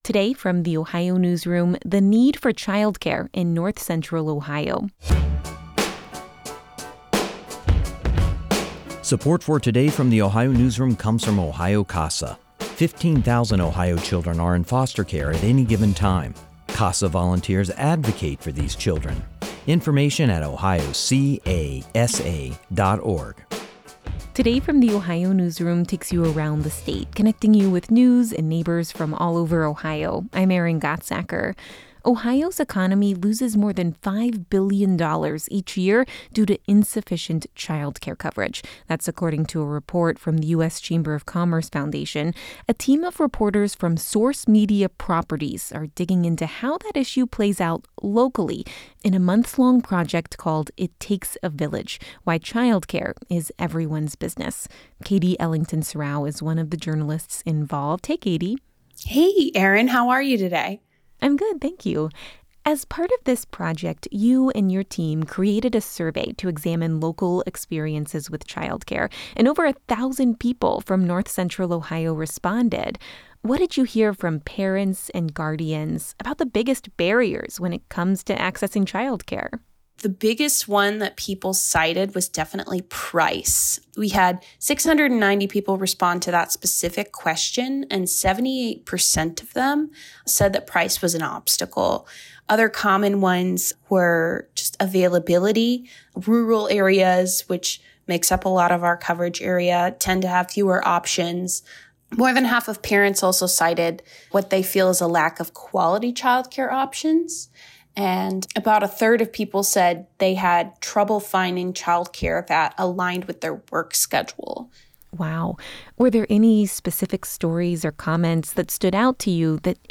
This conversation is based on the Source Media Properties series 'It Takes a Village: Why Child Care is Everyone's Business.'